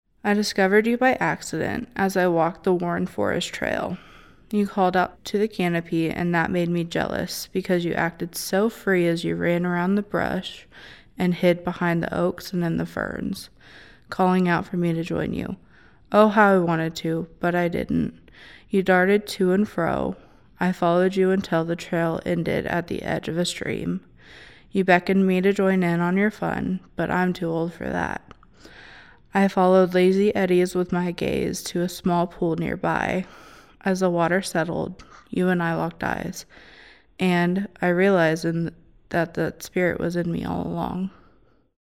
Audio Tour - Power of Poetry